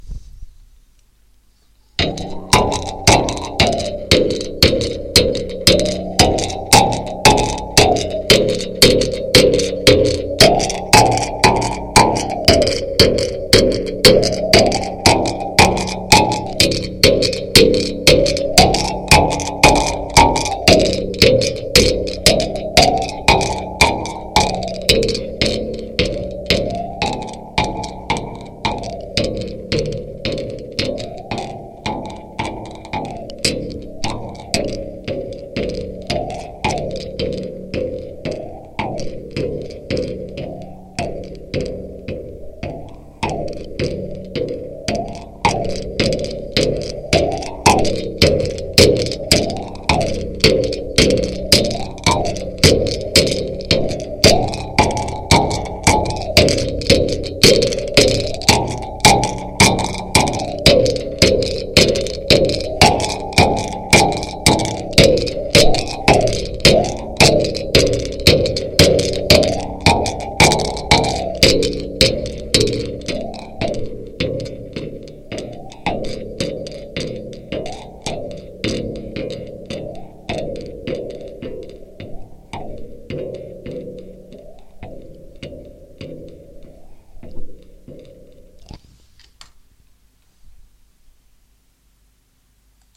描述：春天雨水充沛，碧山村漳河流量大增，奔流有声。录音时间：2021年3月22日；录音器材：ZOOM H3VR
标签： 碧山 皖南村落 漳河 流水有韵
声道立体声